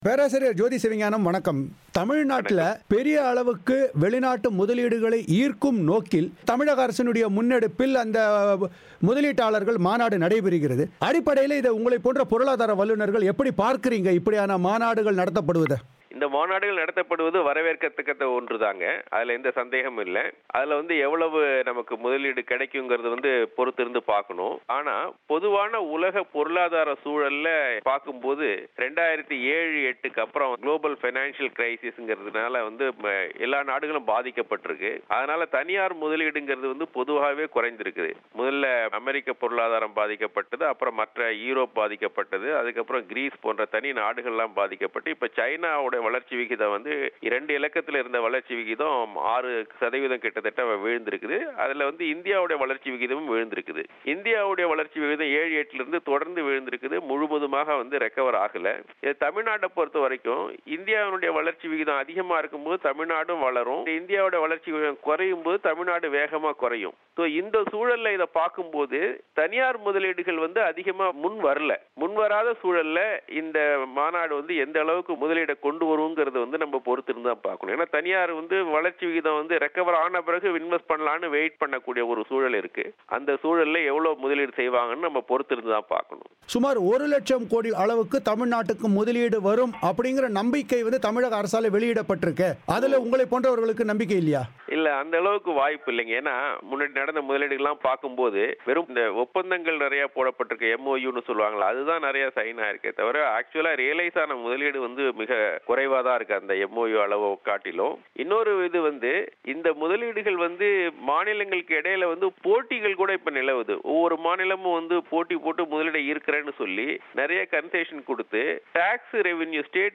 அவர் பிபிசி தமிழோசைக்கு அளித்த பேட்டியை இங்கே கேட்கலாம்.